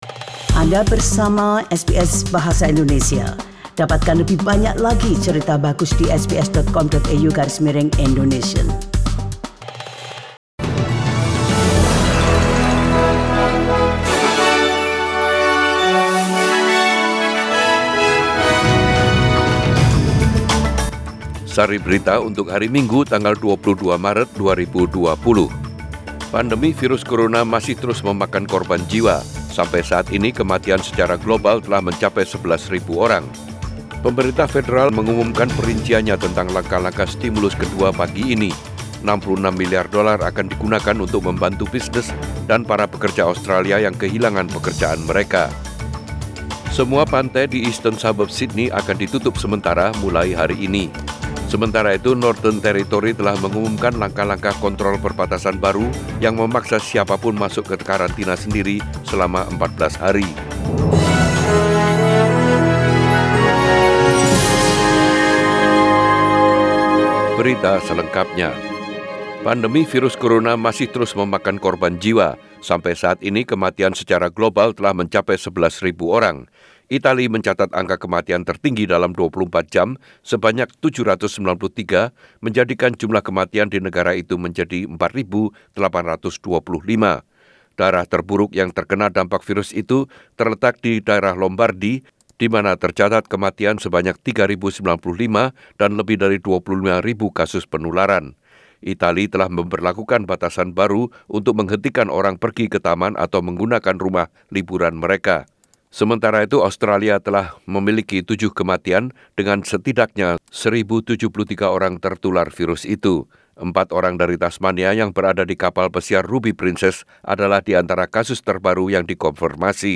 Warta Berita Radio SBS Program Bahasa Indonesia - 22 Maret 2020